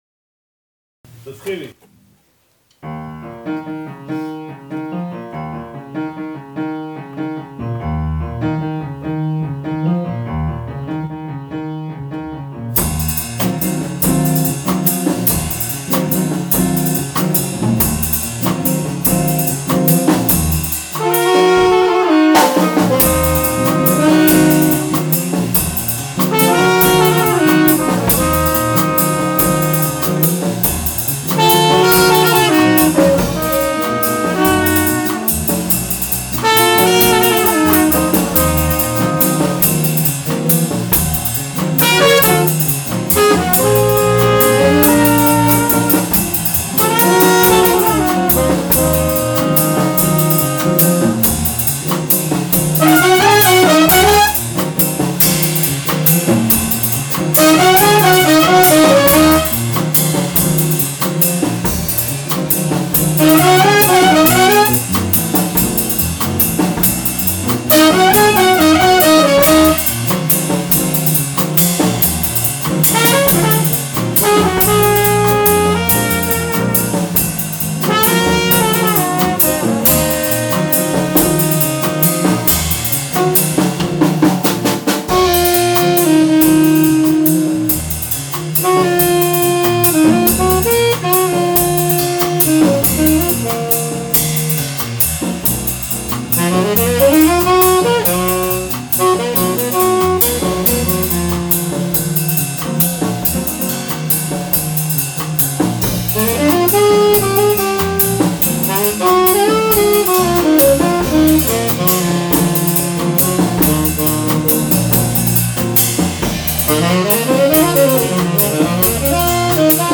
Playing ensemble 30.11.10 - senor blues.mp3